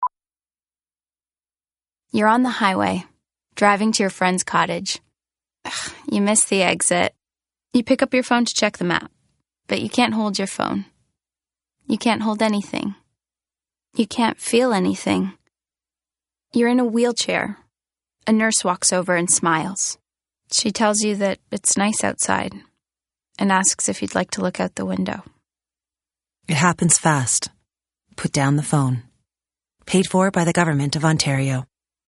BronzePublic Service - Radio Single
It Happens Fast Radio - Cottage